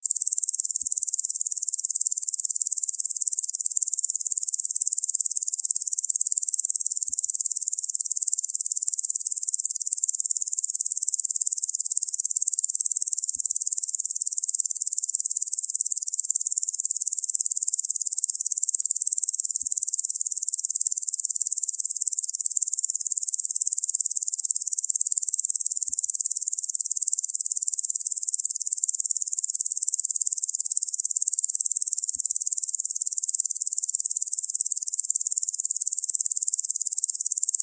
Стрекотание этих насекомых идеально подходит для релаксации, звукового оформления или погружения в атмосферу дикой природы.
Саранча - Альтернативный вариант